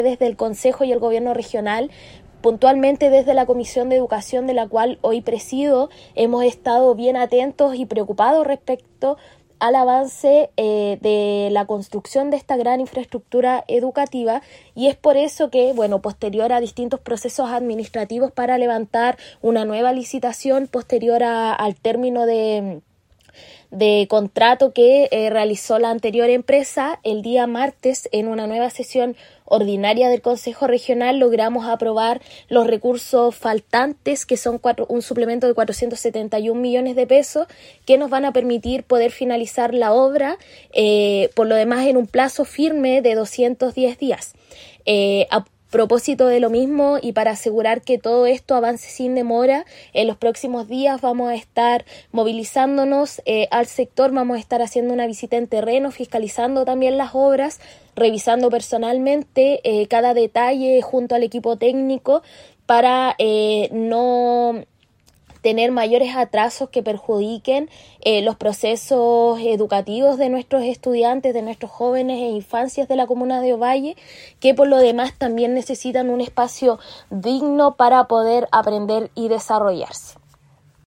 Por su parte, la presidenta de la comisión de Educación y cultura del CORE, Valeria Chacana, agregó que
CORE-Valeria-Chacana-pdta-Educacion-y-Cultura.mp3